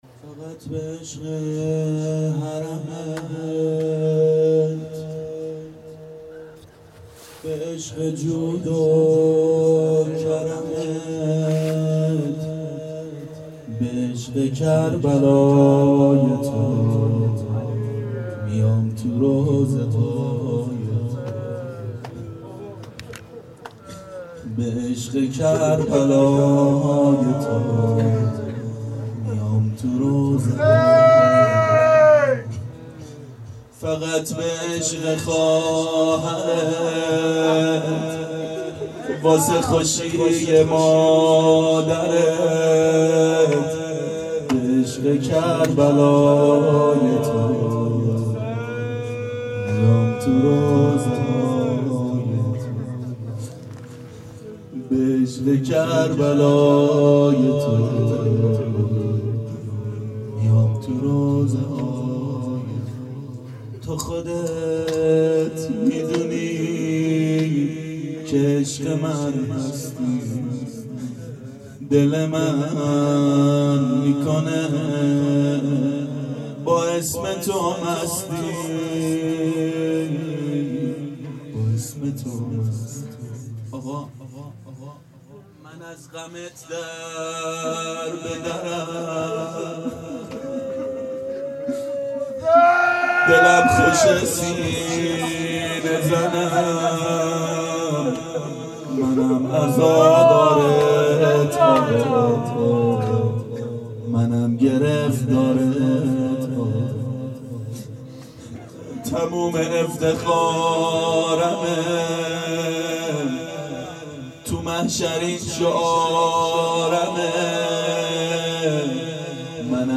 شب سوم محرم 92 هیأت عاشقان اباالفضل علیه السلام منارجنبان